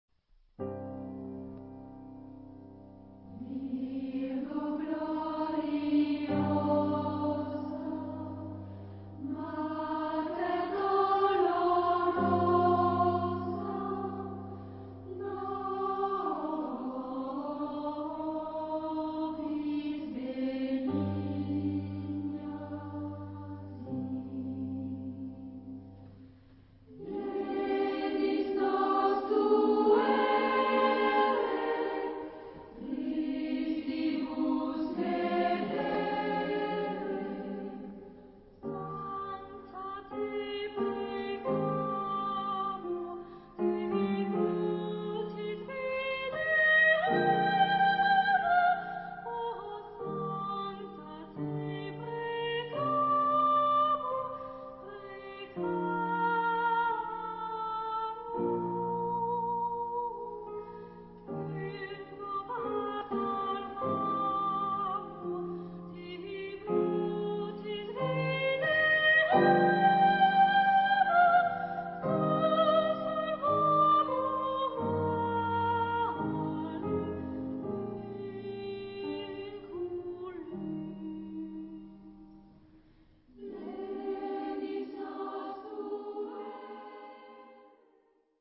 Genre-Style-Forme : Messe ; Sacré
Caractère de la pièce : solennel
Type de choeur : SATB (div)  (4 voix mixtes )
Solistes : Sopran (1)  (1 soliste(s))
Instrumentation : Orgue  (1 partie(s) instrumentale(s))